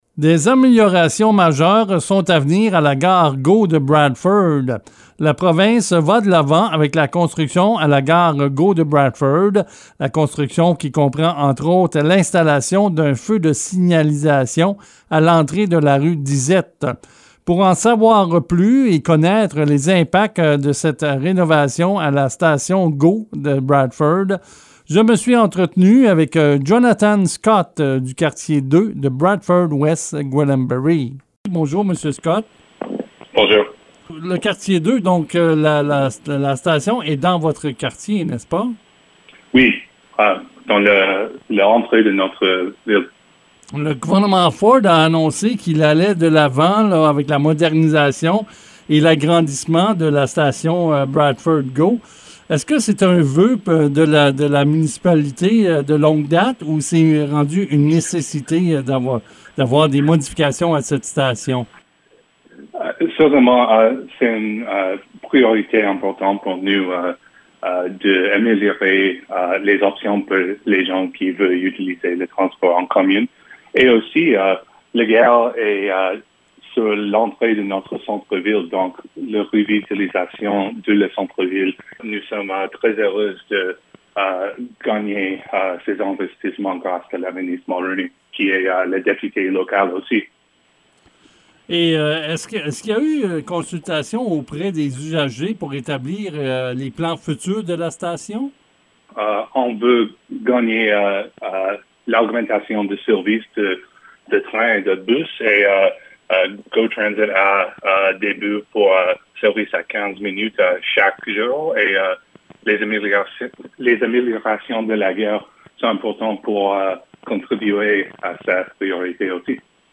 Discutions avec l'échevin du quartier 2 Jonathan Scott au sujet des rénovations à venir de la station Bradford Go :